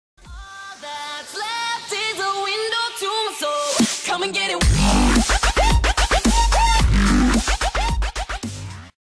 twitch-alert-sound-effect-follower-donation-subscribe-mega-1_94YbA0v.mp3